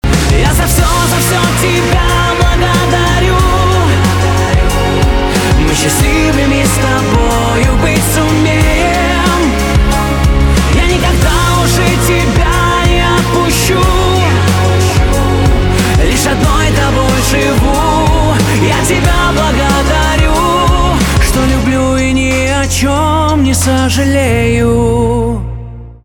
поп
гитара , барабаны
романтические